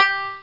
Banjo Sound Effect
Download a high-quality banjo sound effect.
banjo.mp3